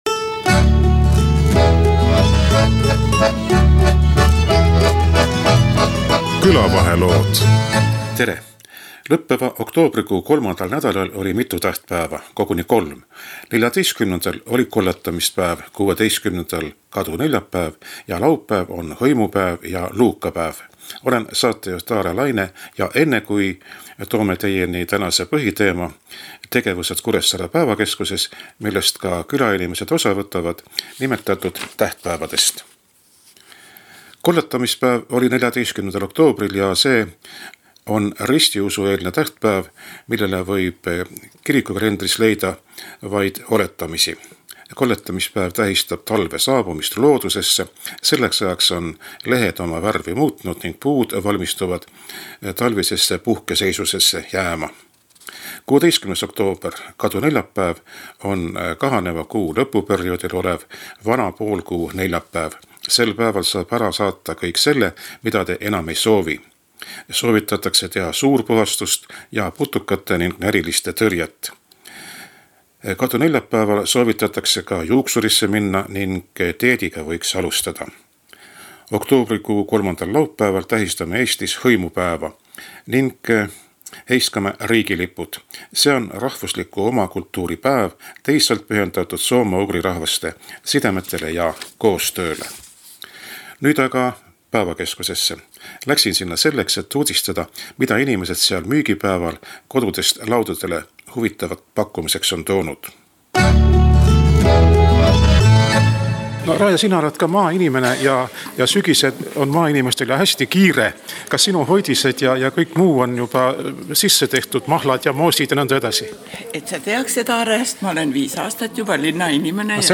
Päevakeskuses käis raadiomees uudistamas, mida nii külainimesed kui ka ammuilma maalt maakonnakeskusesse kolinud inimesed sügislaadal pakkusid. Kuulajatele jagavad infot keskuses toimuvast huviringides osalejad.